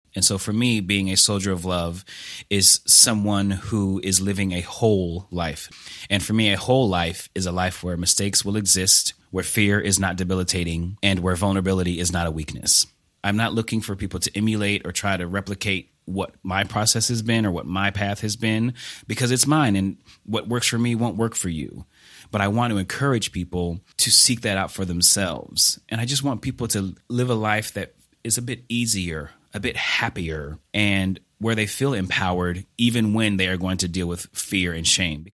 In this interview, he details how he dealt with his trauma, how the theater helps him find his strength, and how he learned to acknowledge and live with fear.